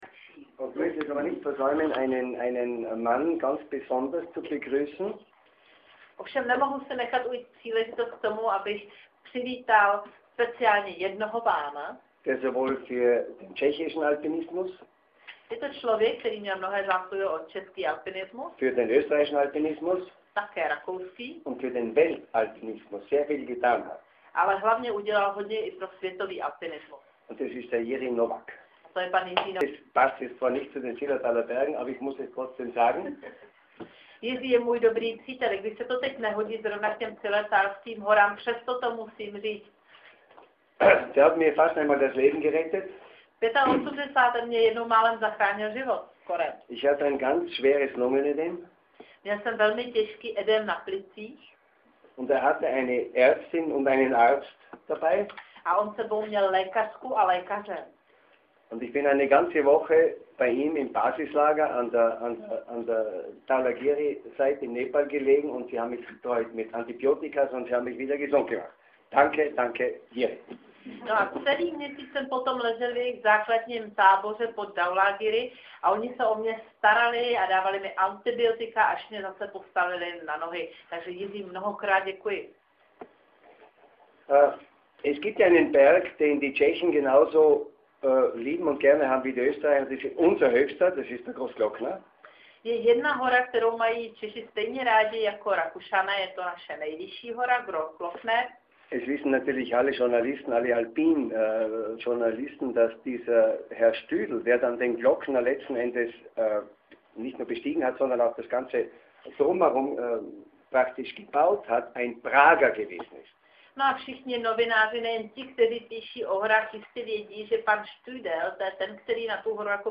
AUDIO: Peter Habeler hovoří o Glockneru, Zillertalu a turistech
Poslechněte si záznam jeho tiskové konference na Nebozízku na Petříně, kam vystoupal s novináři v patách pěšky podél lanovky.